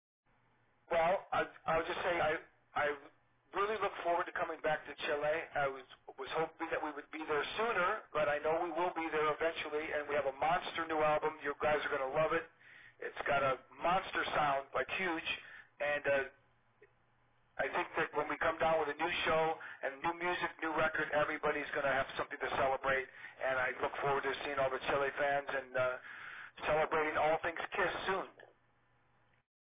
Entrevista: